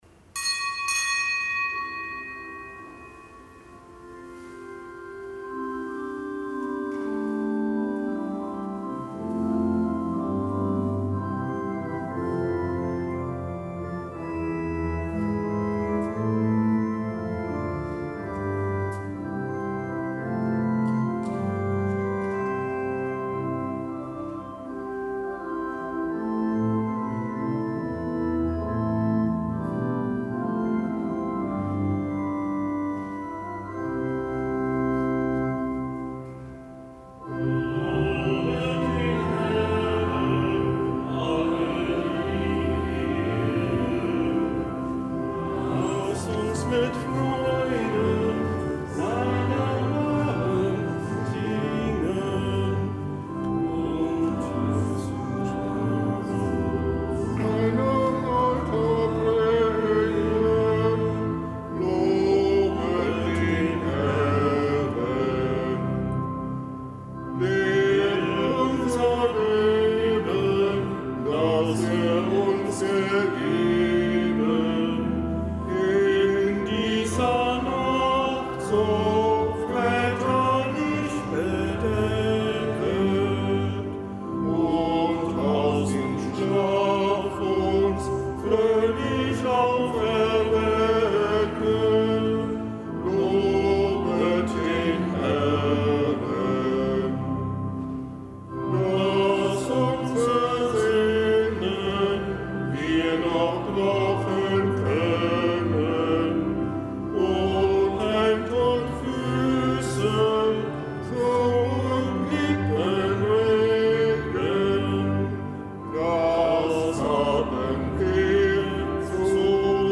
Kapitelsmesse am Montag der sechsten Woche im Jahreskreis
Kapitelsmesse aus dem Kölner Dom am Montag der 6. Woche im Jahreskreis.